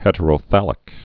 (hĕtə-rō-thălĭk)